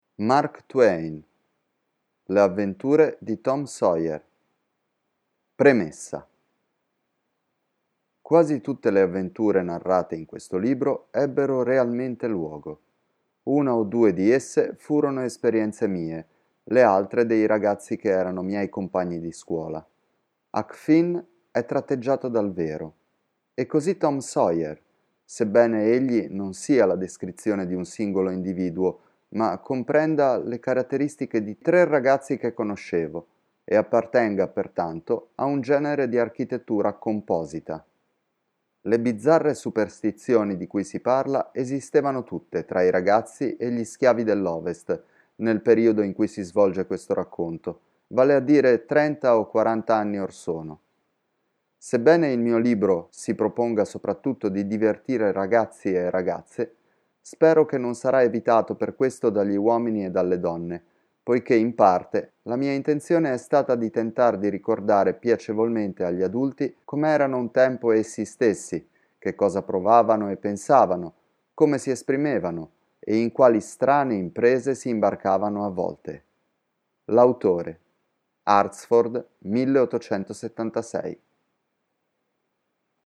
I can change my voice in various ways, making it younger, older, bad, good, sharp, rough, and so on.
Sprechprobe: Sonstiges (Muttersprache):